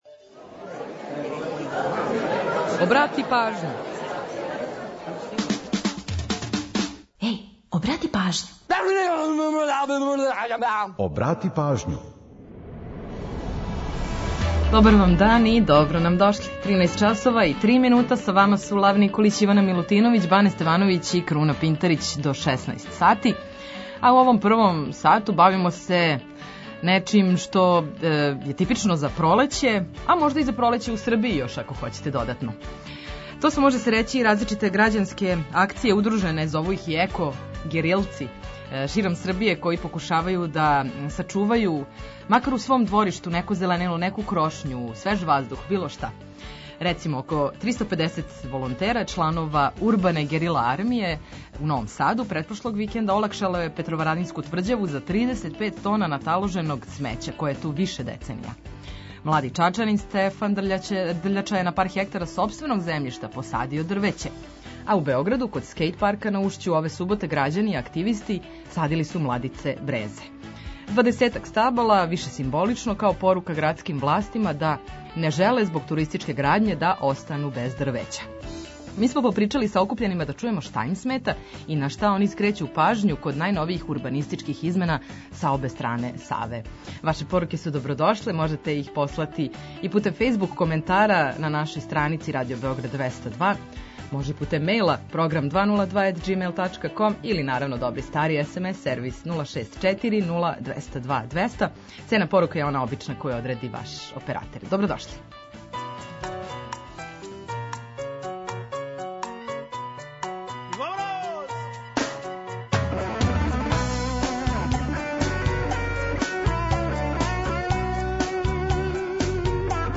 Попричали смо са окупљенима да чујемо шта им смета и на шта скрећу пажњу код најновијих урбанистичких измена са обе стране Саве.